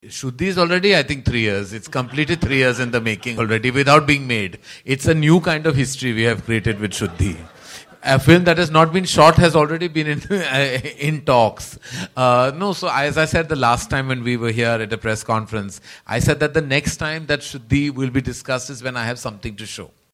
सुनिए किस तरह जवाब दिया निर्माता करण जौहर ने उनकी फ़िल्म 'शुद्धि' पर पूछे गए सवाल का.